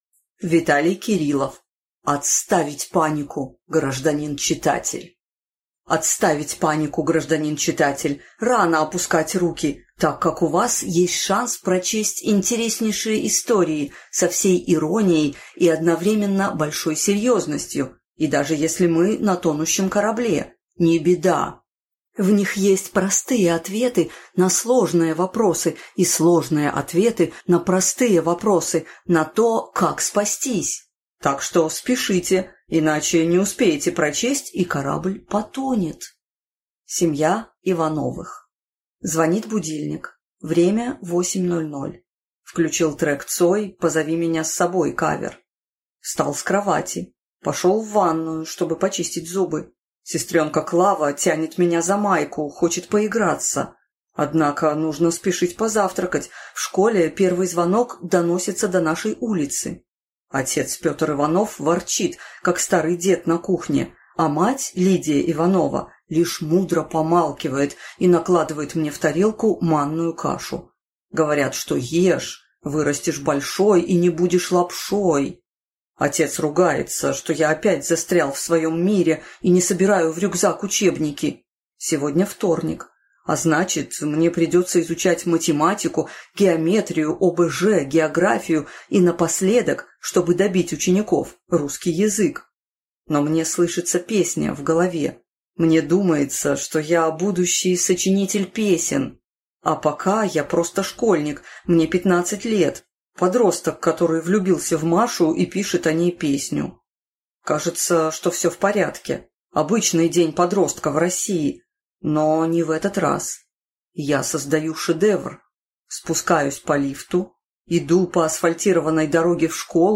Аудиокнига Отставить панику, гражданин читатель!